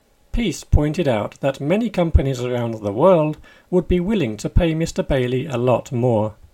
DICTATION 9